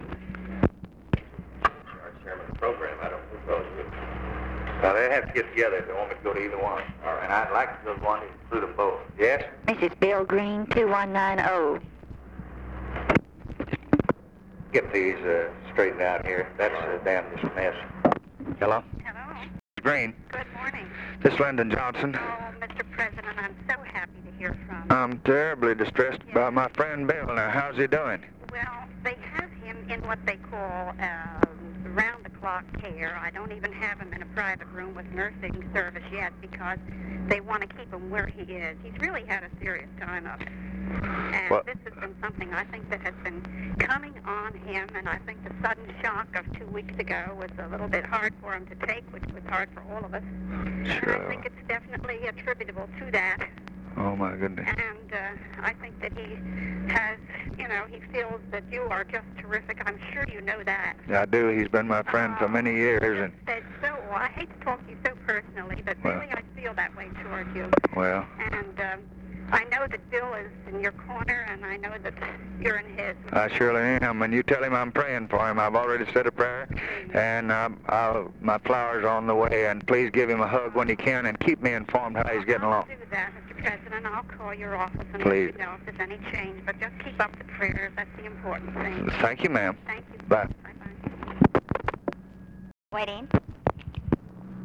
OFFICE CONVERSATION, December 09, 1963
Secret White House Tapes